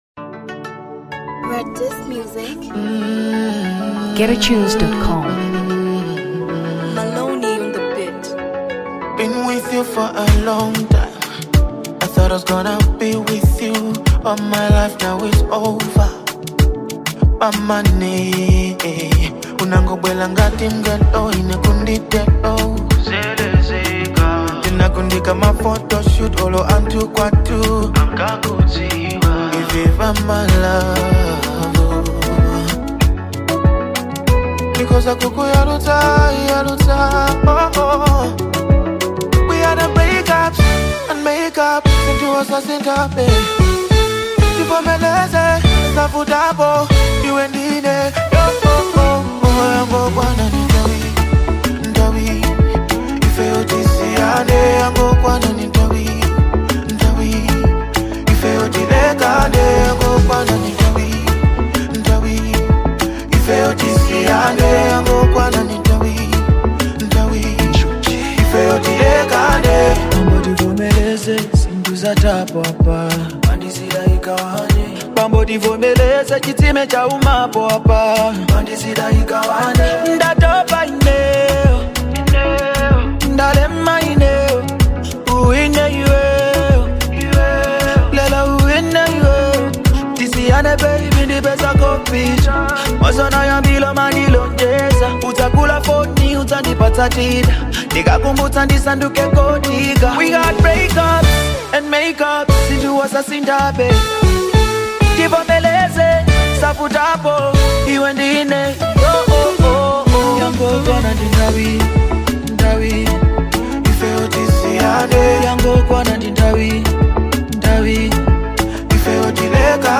Afrobeats 2023 Malawi